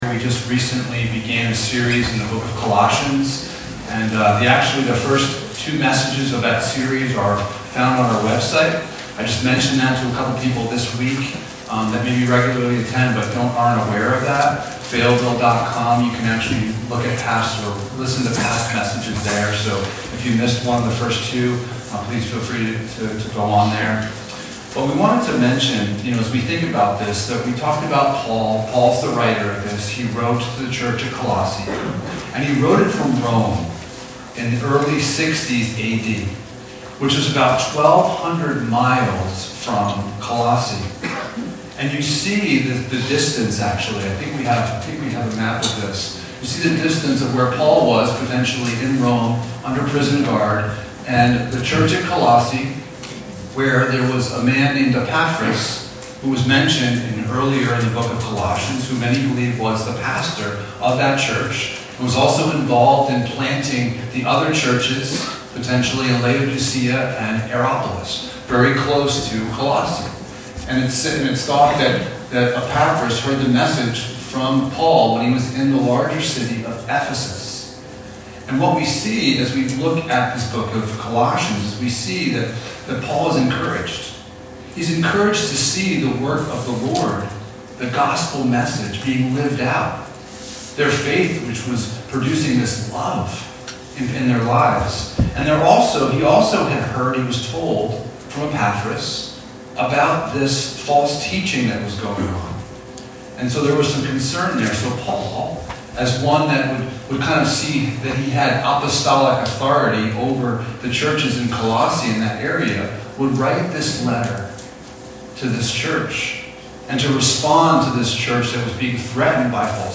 9-14 Service Type: Sunday Morning « The Marks of a Christian Has The Gospel Changed You?